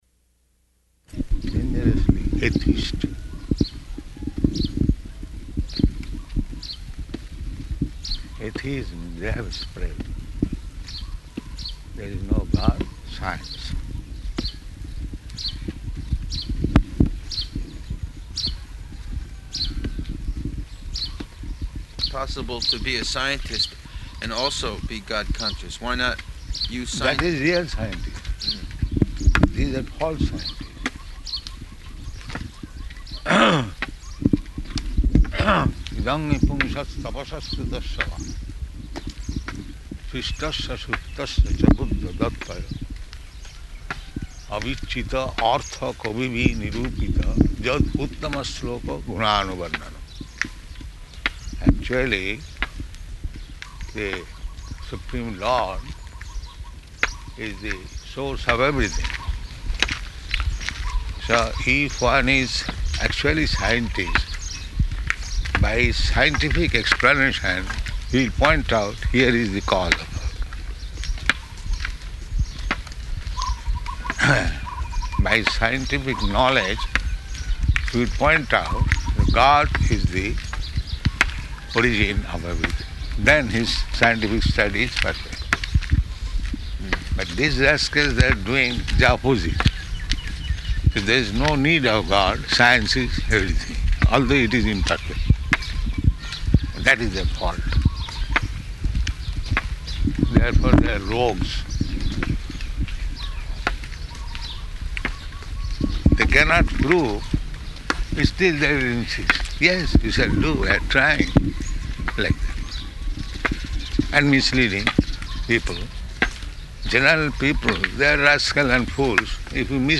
Morning Walk --:-- --:-- Type: Walk Dated: October 2nd 1975 Location: Mauritius Audio file: 751002MW.MAU.mp3 Prabhupāda: ...dangerously atheistic.